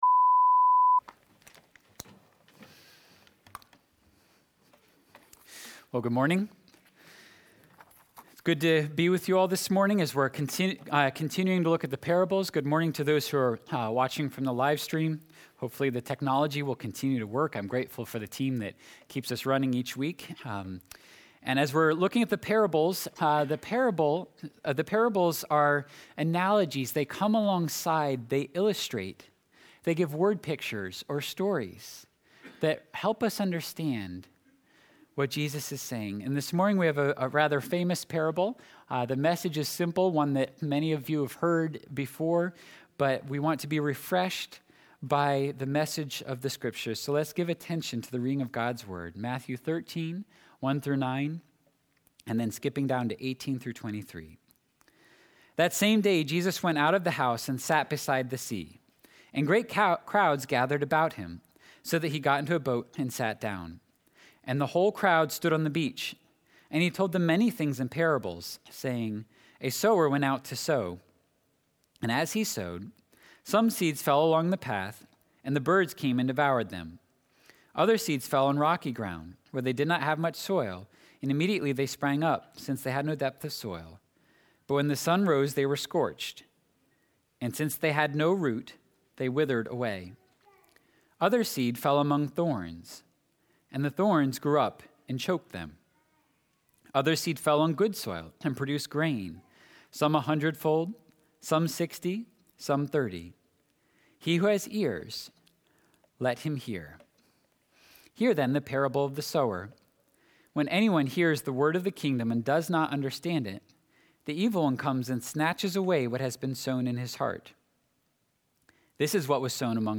1.9.22-sermon-audio.mp3